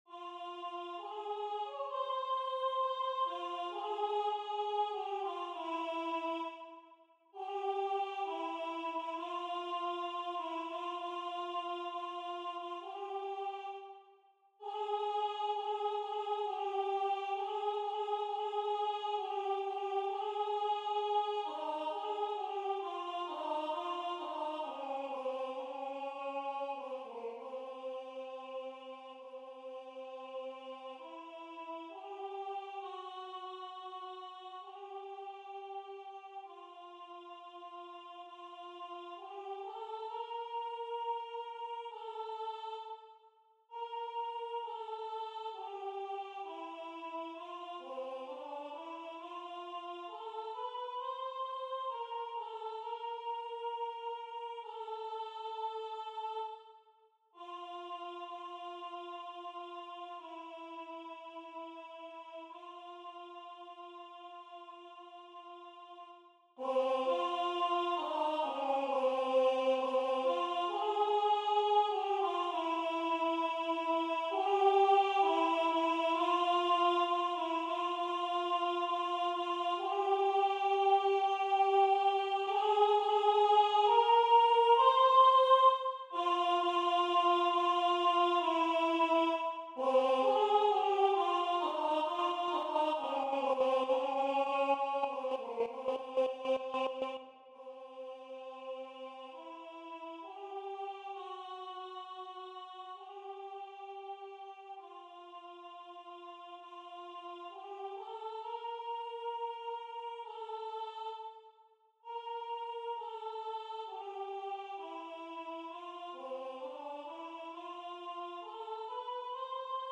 LAS-RUINAS-DEL-MONASTERIO-Alto.mp3